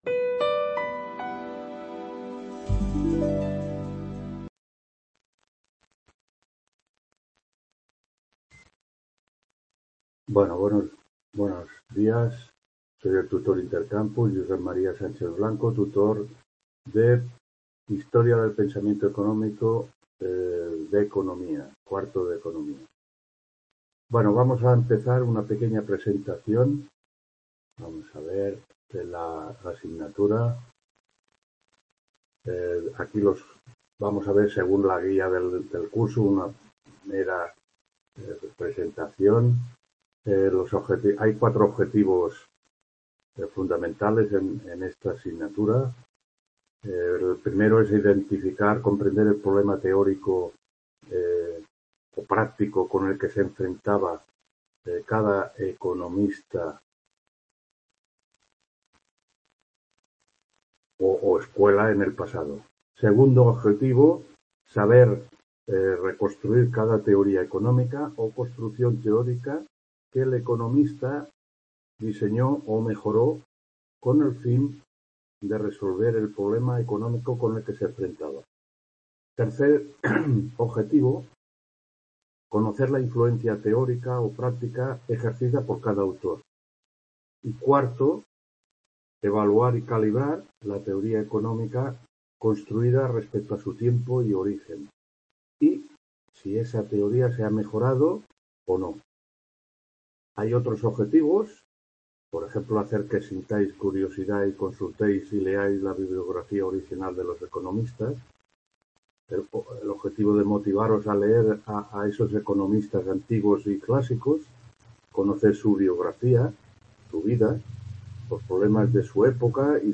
1ª TUTORÍA PRESENTACIÓN Hª PENSAMIENTO ECONÓMICO (Nº…